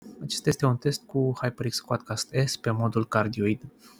Sunet clar pentru toate modurile alese
• Cardioid
Este modul pe care l-am utilizat cel mai des, deoarece este perfect pentru streaming sau când este utilizat de către o singură persoană deoarece preia sunetul din fața acestuia blocând celalte sunete din lateral.